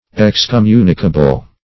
Search Result for " excommunicable" : The Collaborative International Dictionary of English v.0.48: Excommunicable \Ex`com*mu"ni*ca*ble\, a. [See Excommunicate .]